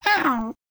sounds / monsters / cat / 5.ogg